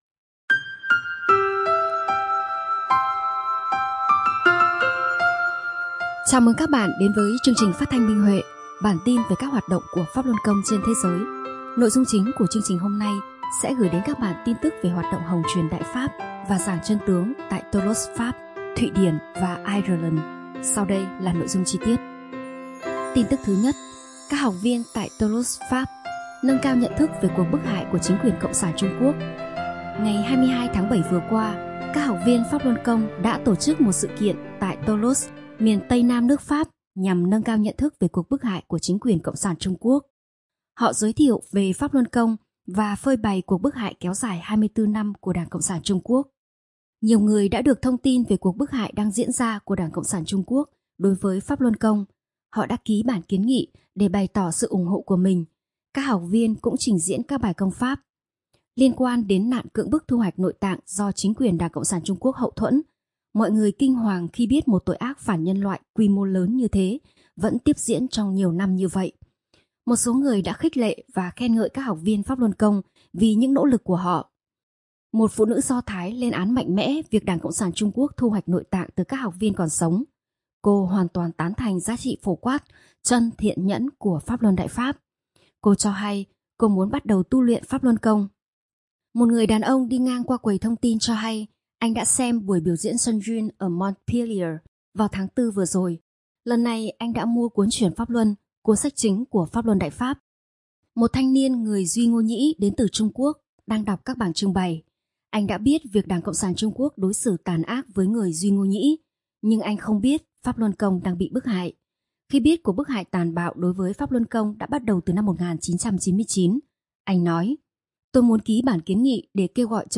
Chương trình phát thanh số 51: Tin tức Pháp Luân Đại Pháp trên thế giới – Ngày 4/8/2023